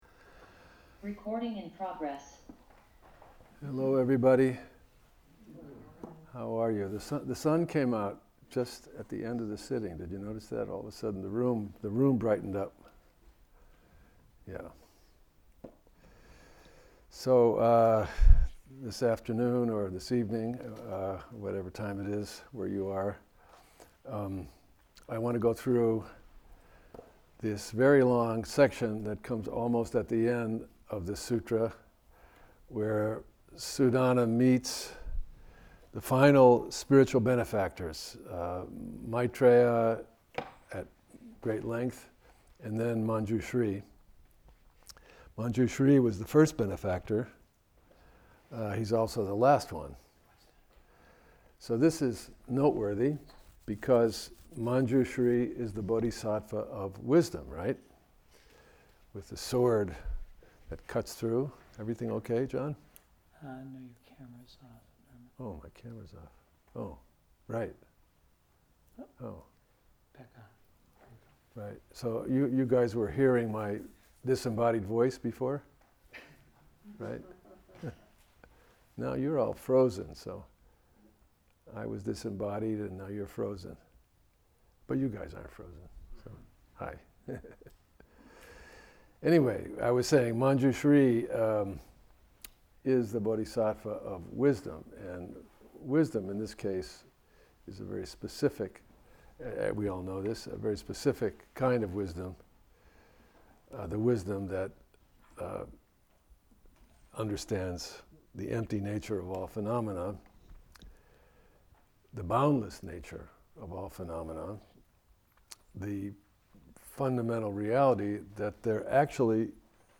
Dharma Talks from the Everyday Zen Foundation